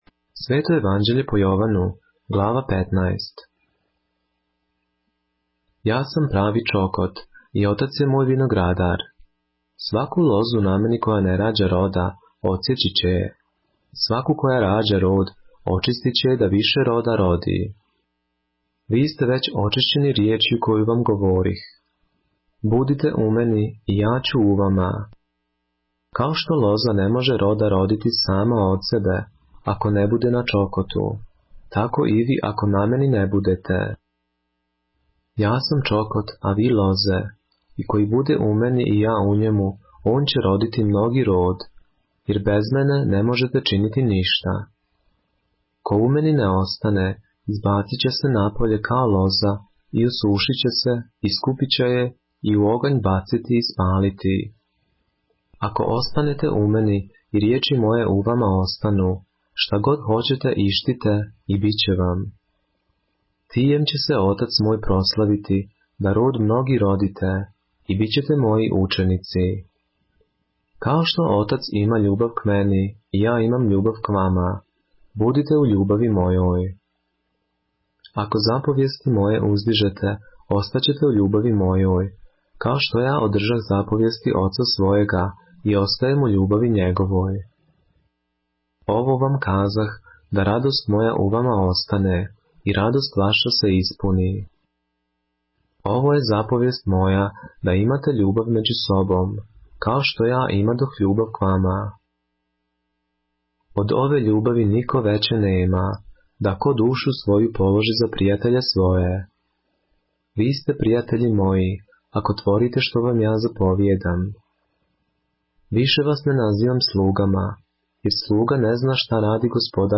поглавље српске Библије - са аудио нарације - John, chapter 15 of the Holy Bible in the Serbian language